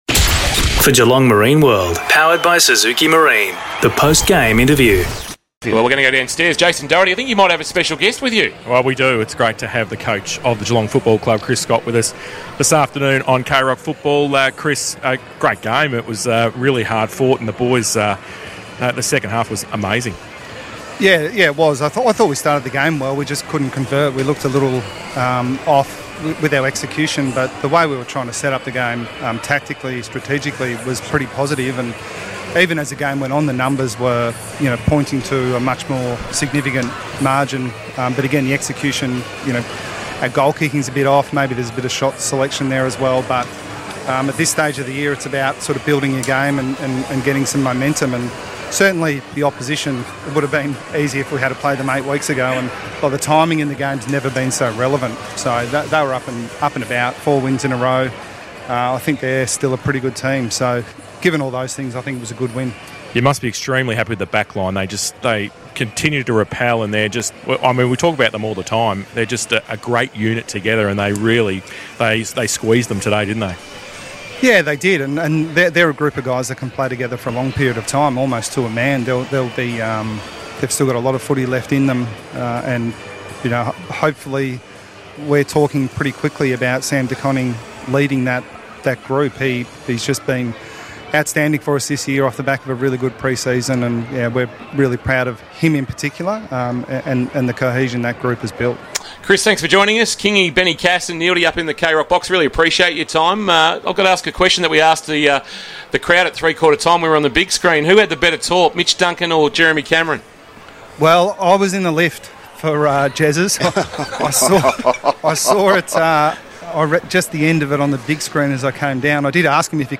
2022 - AFL ROUND 10 - GEELONG vs. PORT ADELAIDE: Post-match Interview - Chris Scott (Geelong Coach)